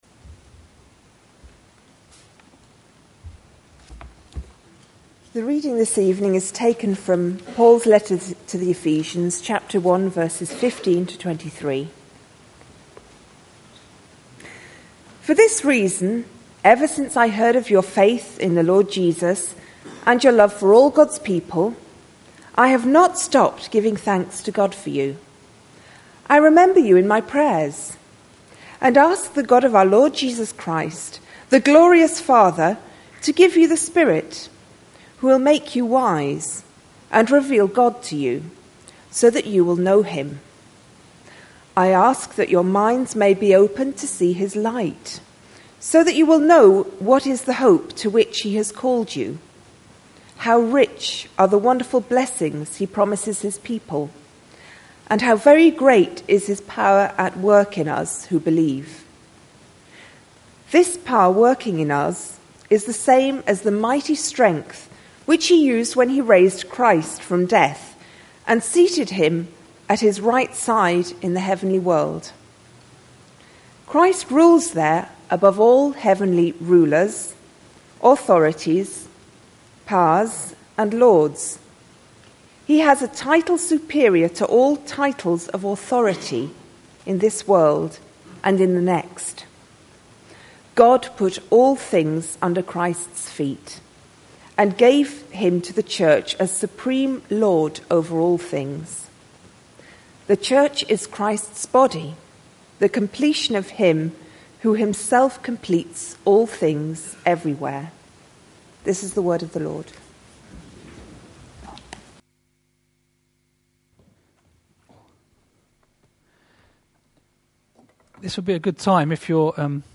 A sermon preached on 20th May, 2012, as part of our The Trinity in Action series.